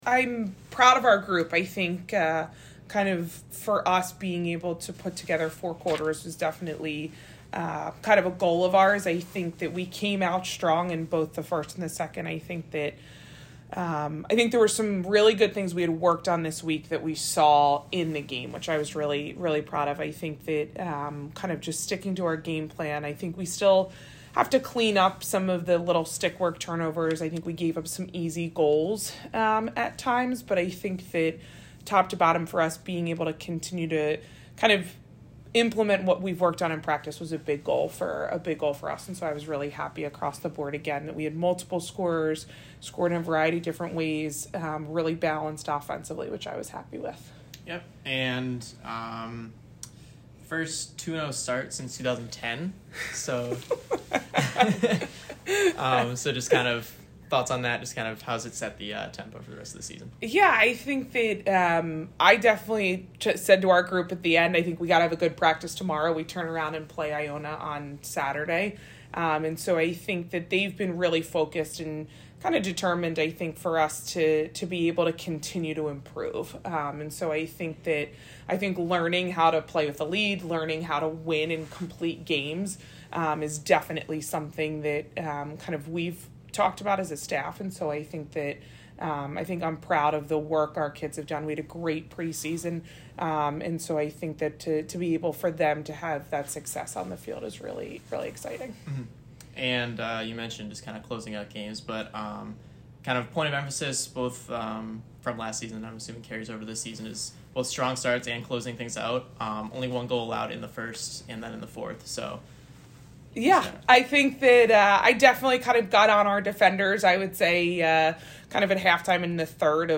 Stonehill Postgame Interview